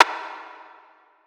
MetroBatSnare.wav